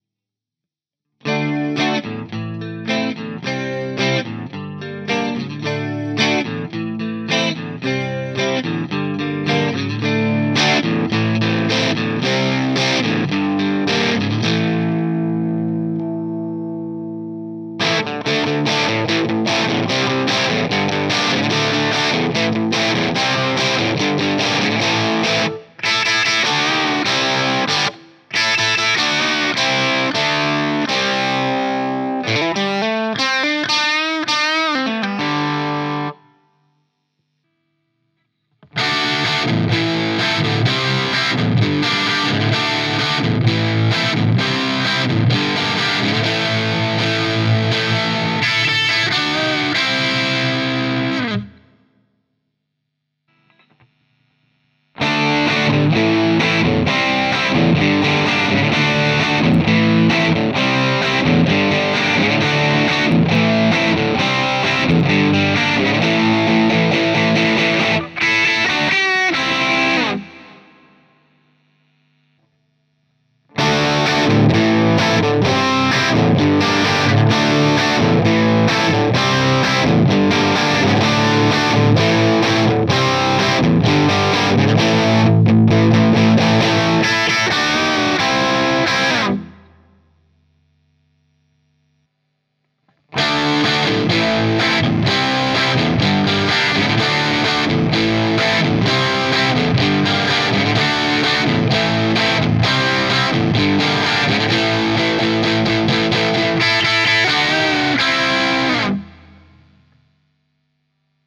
Quelques-uns de mes presets crunchy . Sur le premier, je peux passer d'un clean/chorus à un saturé sec à la pédale.
CrunchyGain.mp3